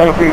IDG-A32X/Sounds/GPWS-OLD/altitude-40.wav at 312867ac60cb72b38042d0fdf5d564304d3d87dd